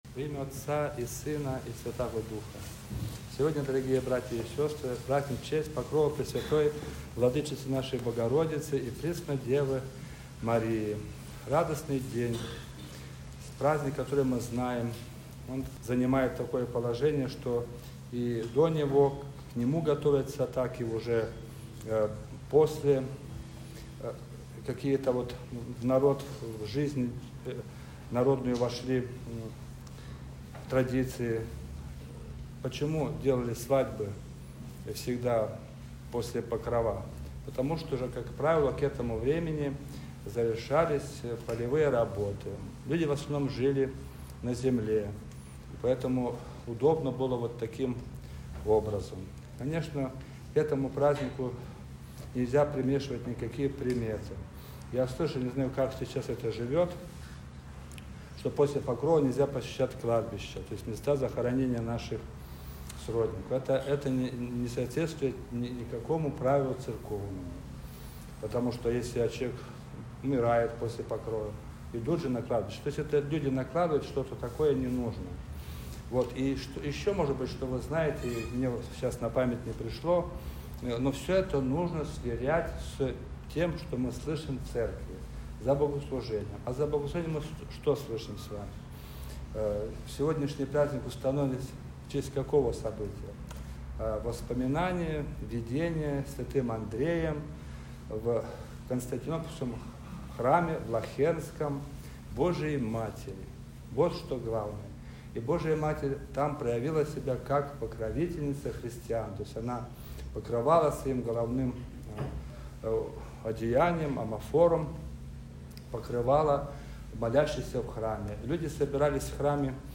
Покров-Пресвятой-Богородицы.mp3